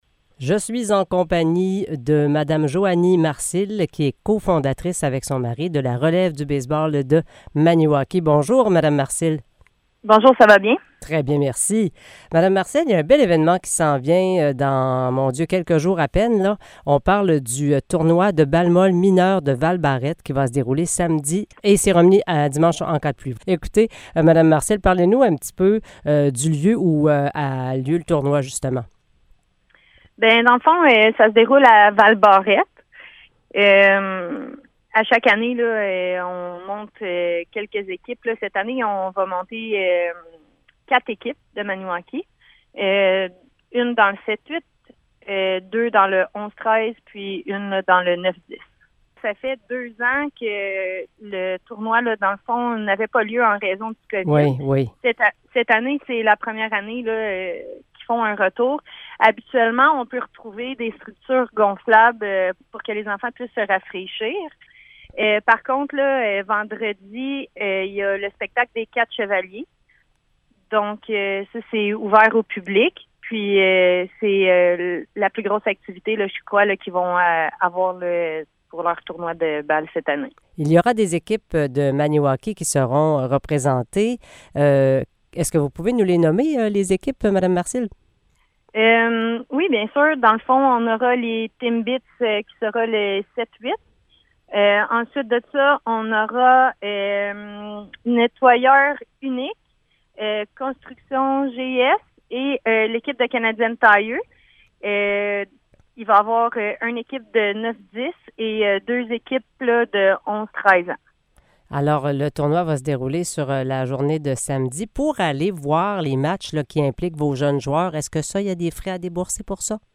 Entrevue sur le tournoi de balle molle de Val-Barette qui aura lieu samedi
entrevue-sur-le-tournoi-de-balle-molle-de-val-barette-qui-aura-lieu-samedi.mp3